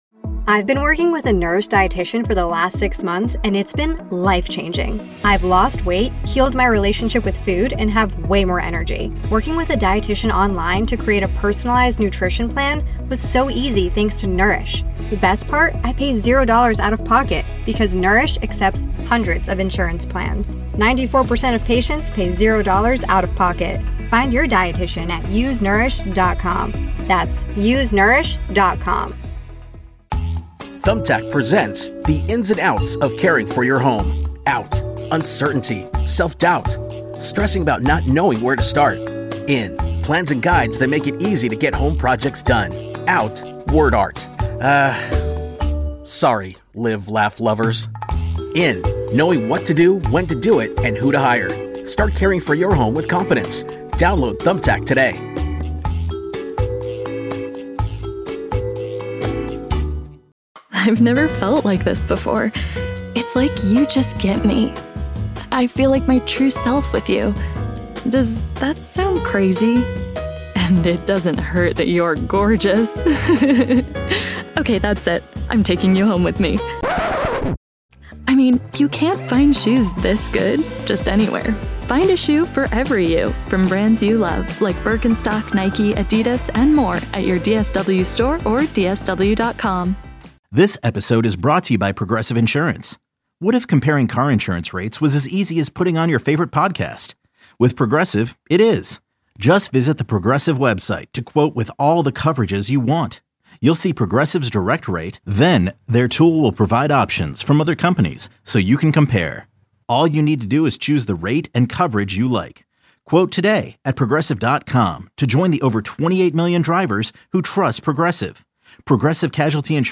The nightly program has been a fixture on KMOX for many years and features a variety of hosts.